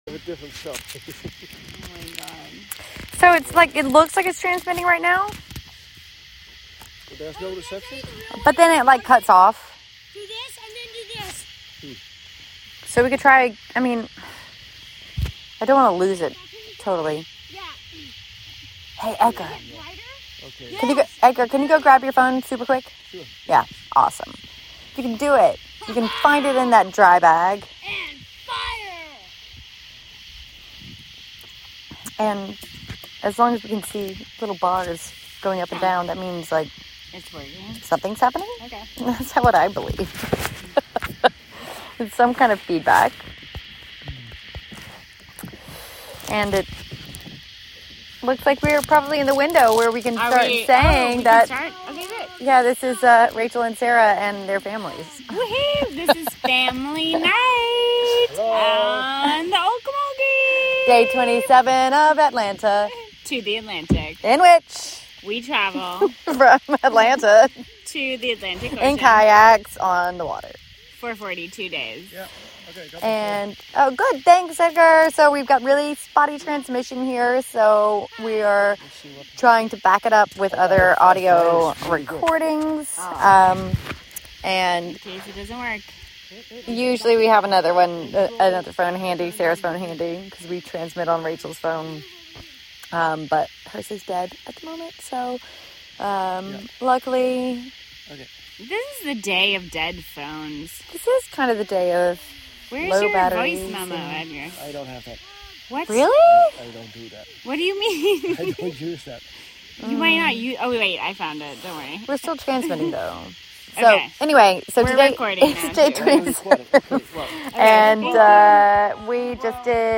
Live from Flux Projects: Atlanta to the Atlantic, day 27 (Audio)